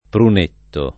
Prunetto [ prun % tto ]